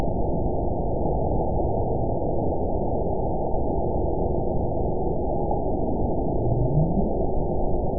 event 912441 date 03/27/22 time 00:07:50 GMT (3 years, 9 months ago) score 9.64 location TSS-AB03 detected by nrw target species NRW annotations +NRW Spectrogram: Frequency (kHz) vs. Time (s) audio not available .wav